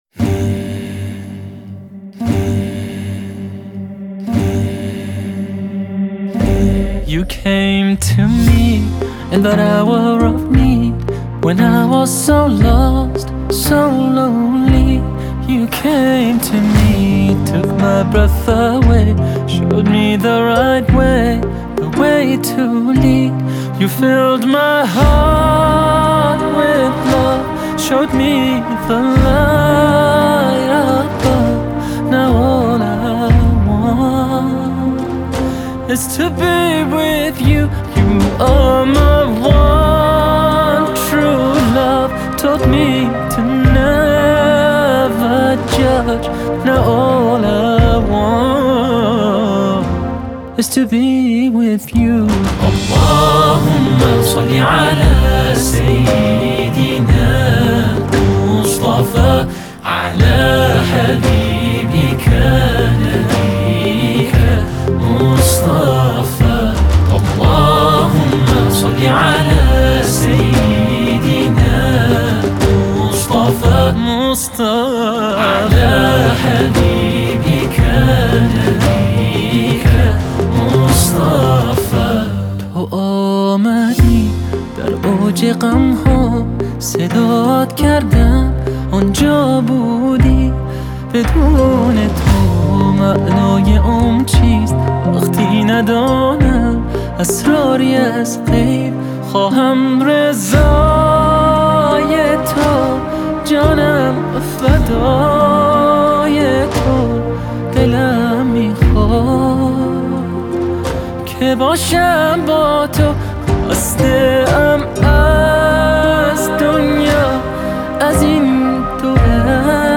British Muslim singer-songwriter
Please take time and listen to the wonderful Islamic Songs